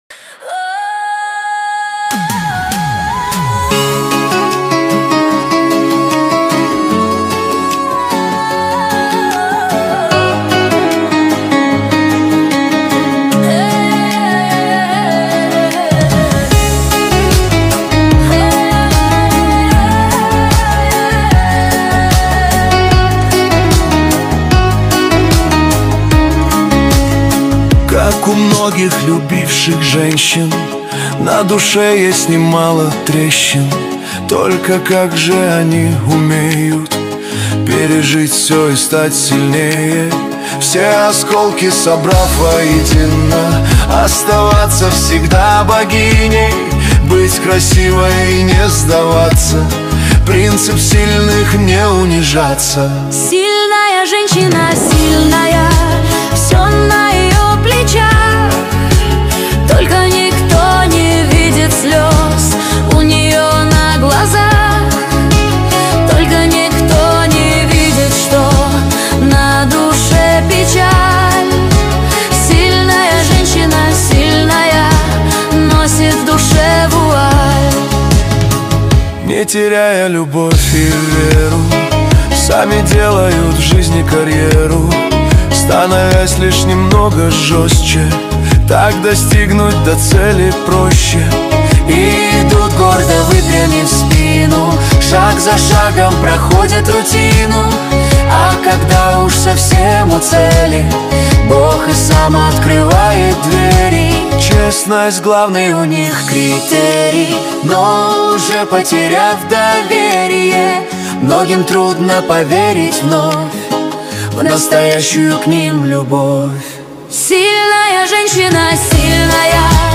Качество: 320 kbps, stereo
Стихи, Нейросеть Песни 2025